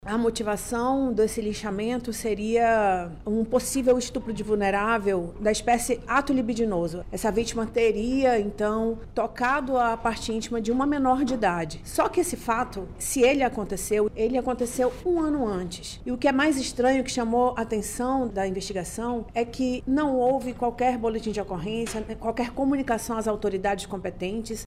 Ainda segundo a delegada, a motivação do crime teria sido um suposto estupro de vulnerável que teria sido cometido pela vítima, o que não foi comprovado.